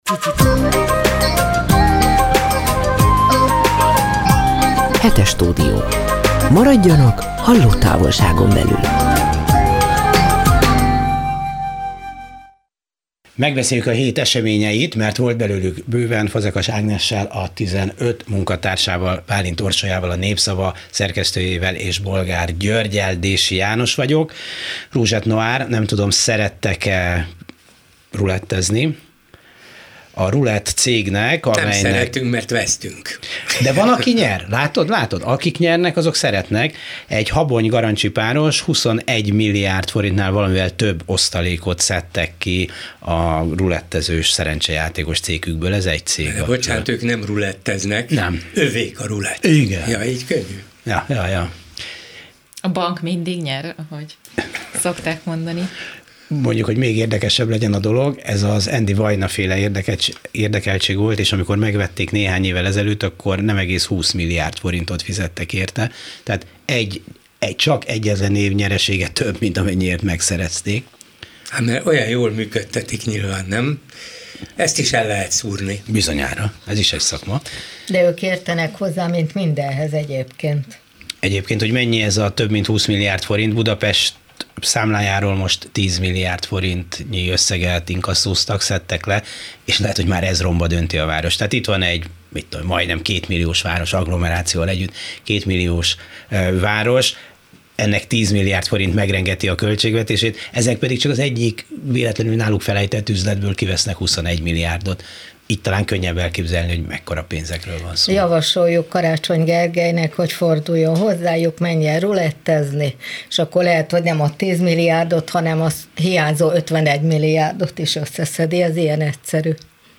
Hétről hétre a legfontosabb történéseket vitatjuk meg újságíró kollégákkal a Klubrádió stúdiójában.